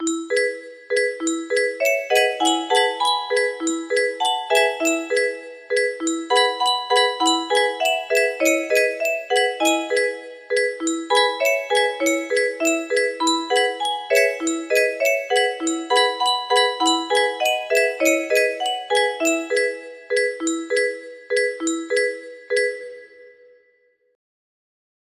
sum bullshit i cooked because I like procrasticating :) music box melody